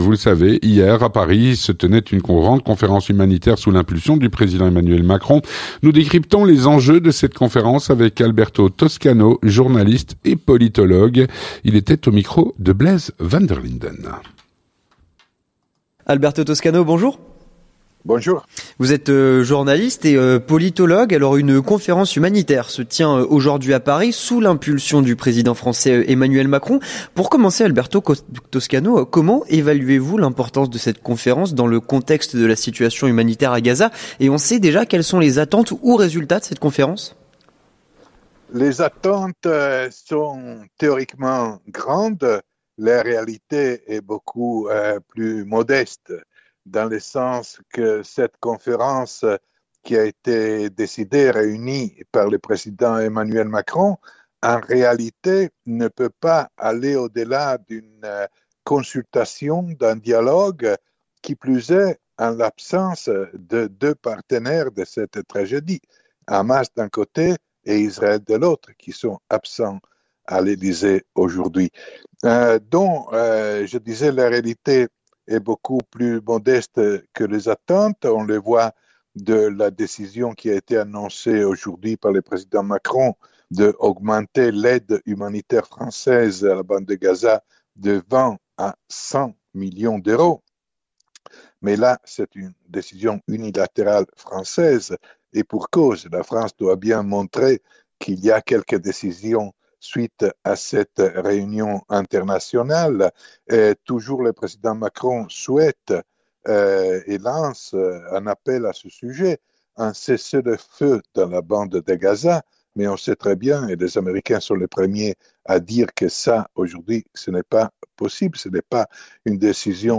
L'entretien du 18H - Retour sur les enjeux de la grande conférence humanitaire qui s'est tenue hier à Paris.